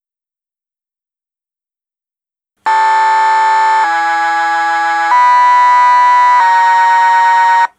BACH Türgong
Es präsentiert sich uns ein Türgong, der auf Knopfdruck die Tonfolge B-A-C-H abspielt. Aber nicht nur die einfachen Töne, sondern jeweils gleich einen harmonischen Dreiklang dazu.
Trickreich verschaltet ergeben sich dann vier Phasen, die jeweils drei andere Tonhöhen erzeugen.
Trickreich geschaltet gibt das eine eindrucksvolle Tonfolge.
bach1.wav